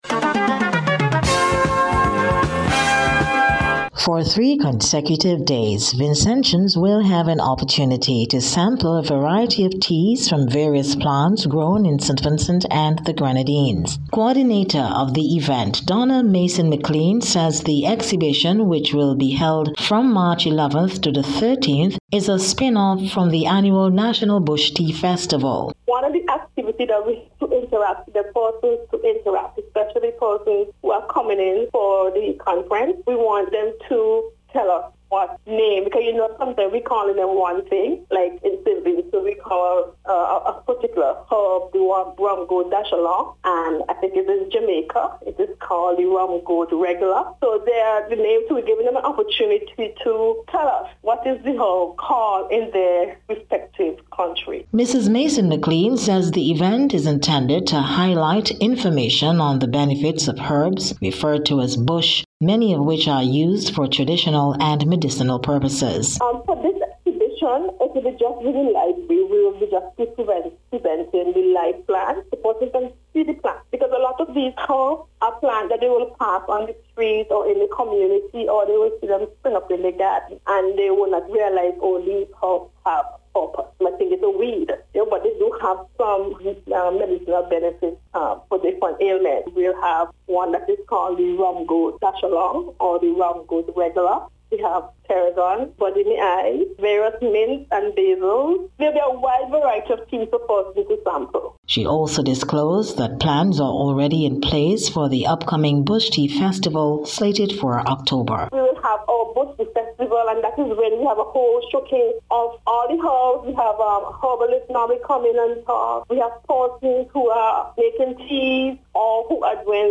NBC’s Special Report- Tuesday 10th March 2026